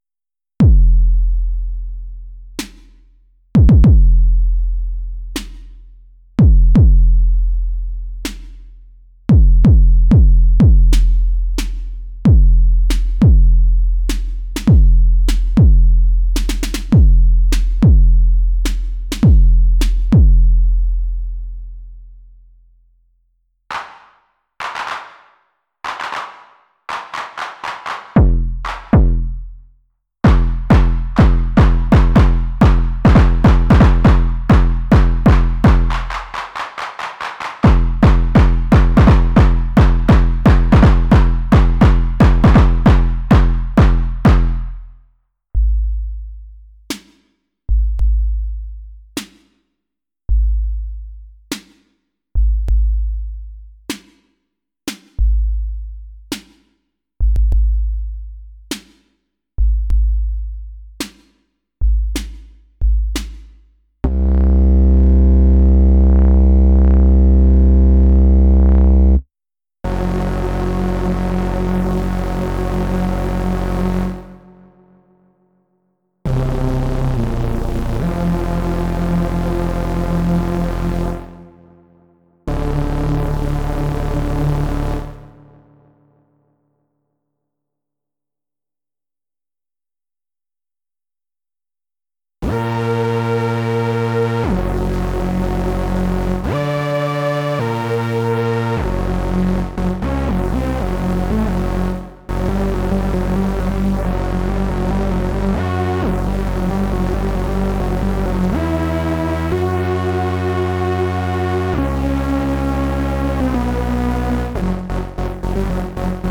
Check Check 1,2 1,2 (3 different drums, a drone, and a hoover)
FX SY Dual VCO (ogg vorbis because size, may not load in Safari on Phone)
So great what you can do with 2 LFOs.